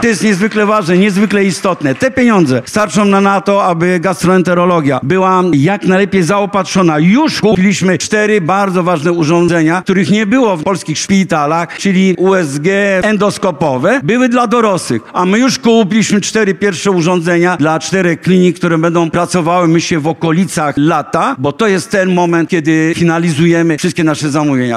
Owsiak – mówi Jurek Owsiak, dziennikarz, działacz charytatywny oraz współzałożyciel i prezes zarządu Fundacji Wielka Orkiestra Świątecznej Pomocy.